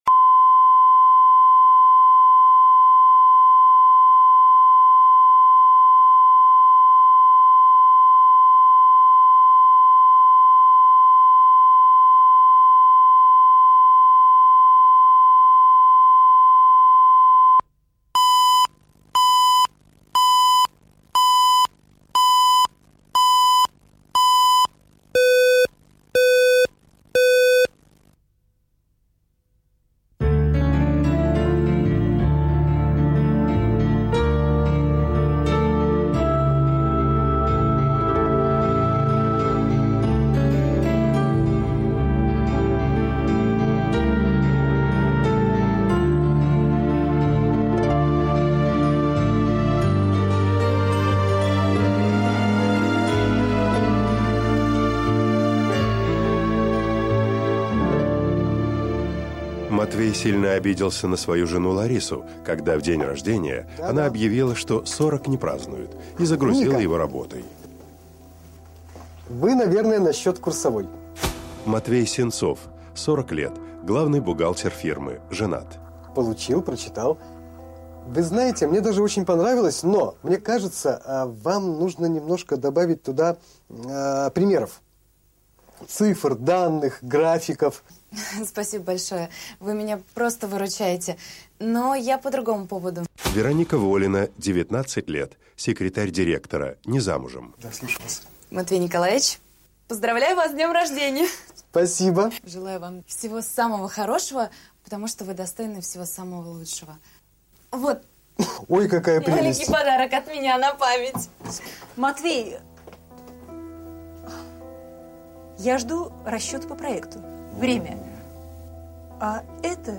Аудиокнига Сорок не празднуют | Библиотека аудиокниг